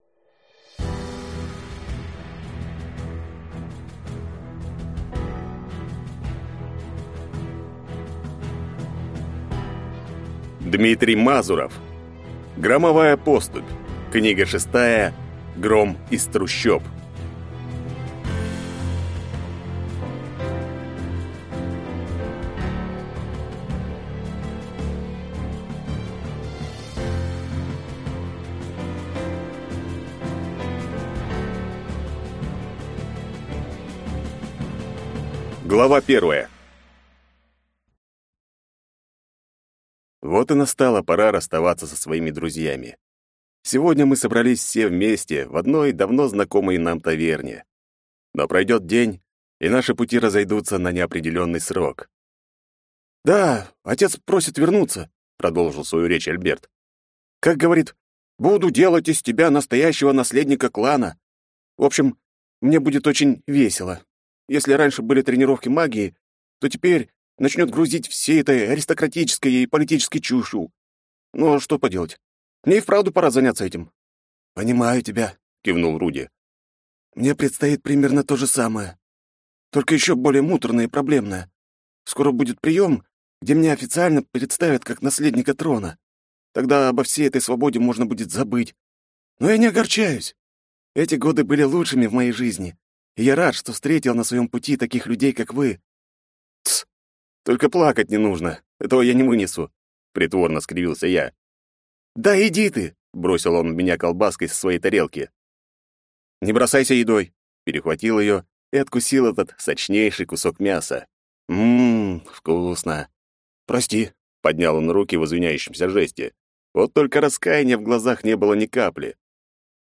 Аудиокнига Громовая поступь 6. Гром из трущоб | Библиотека аудиокниг